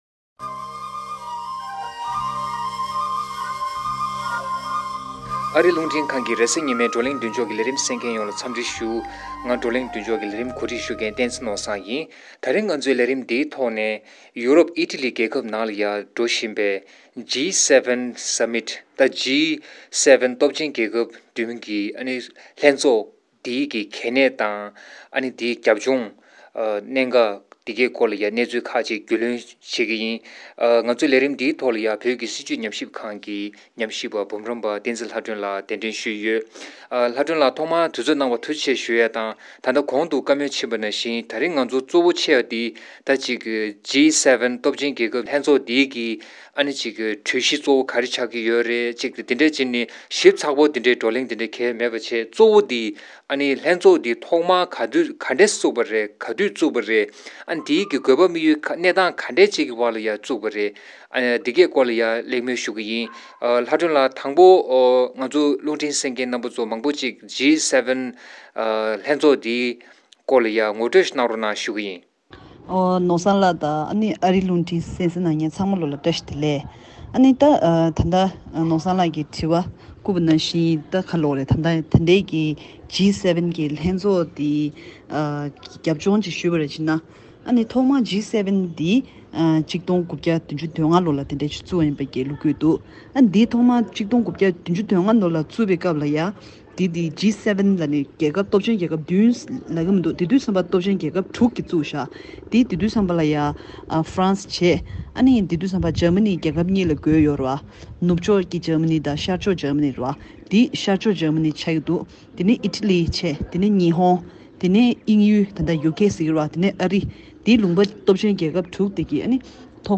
འབྱོར་ལྡན་རྒྱལ་ཁབ་བདུན་གྱི་ཚོགས་འདུའི་སྐོར་གླེང་བ།